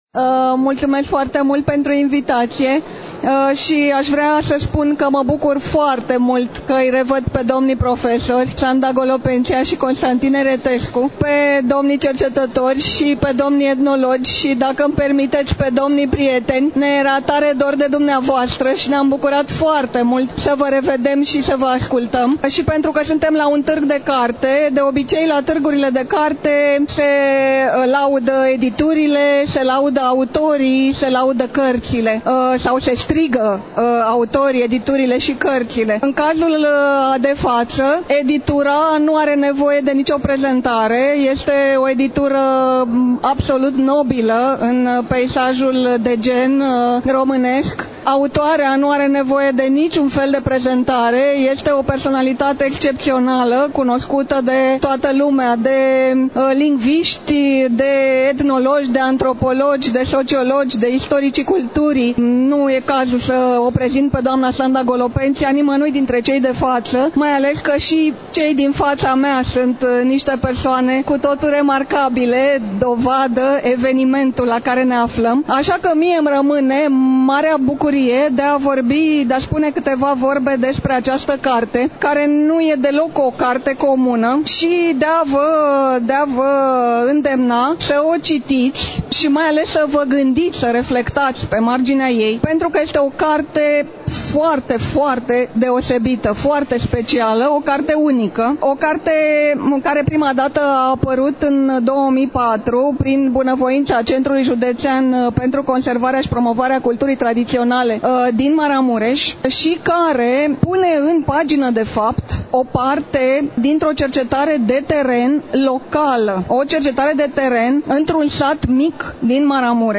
Astăzi, relatăm de la Târgul de Carte Gaudeamus Radio România, ediția a XXIX-a, 7 – 11 decembrie 2022, Pavilionul B2 al Complexului Expoziţional Romexpo, București.